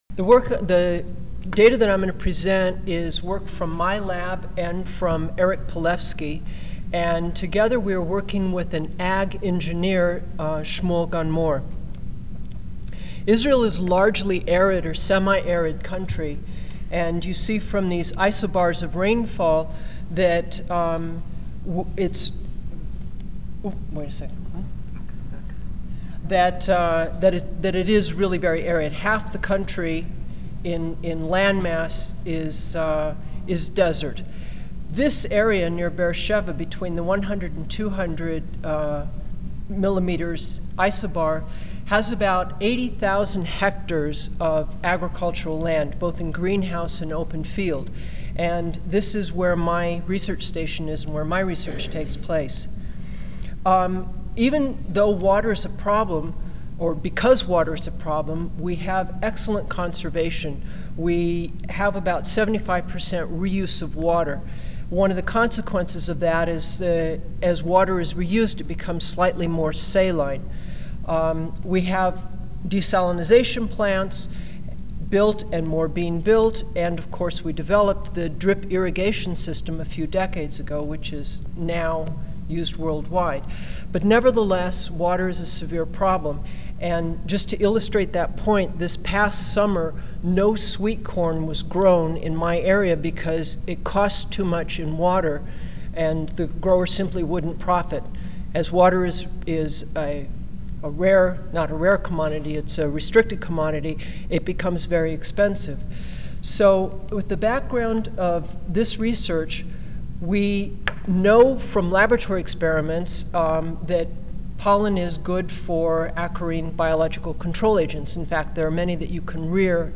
Room A3, First Floor (Reno-Sparks Convention Center)